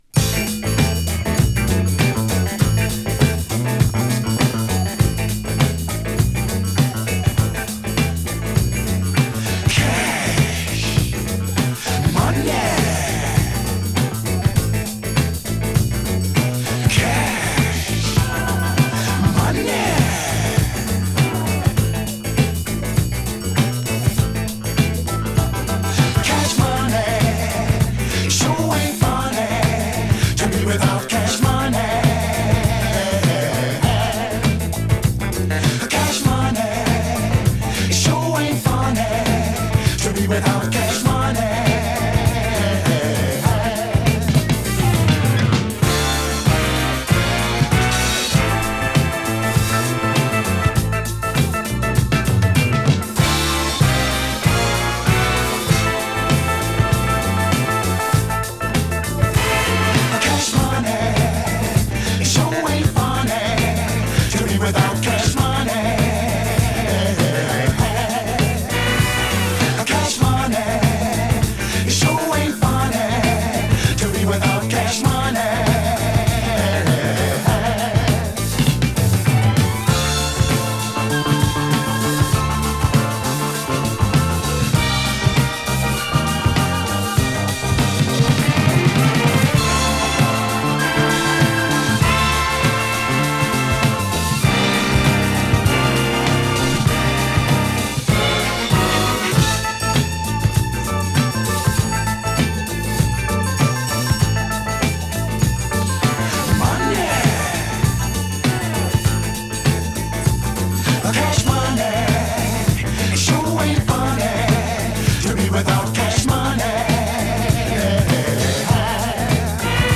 魅惑のストリングスワークや電化したグルーヴで、現行のディスコシーンでも人気の高い。